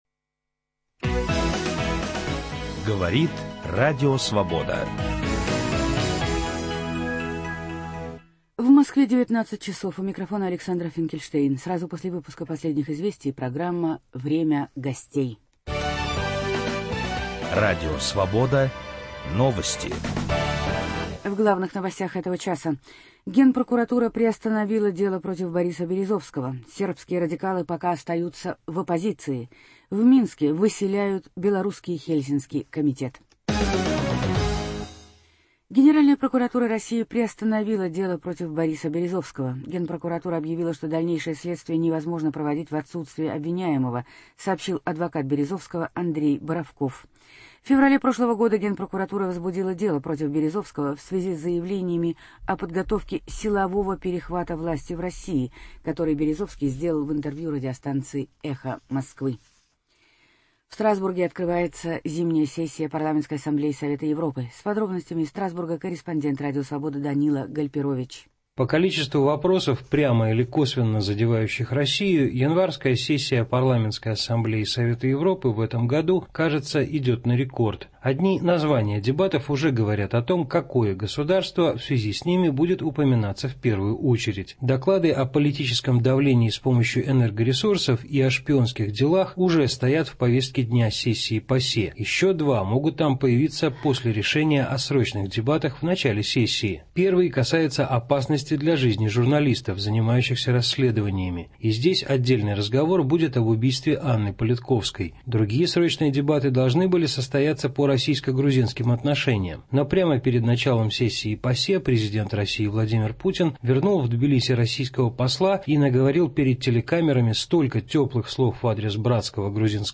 Украинский кризис продолжается. В киевской студии Радио Свобода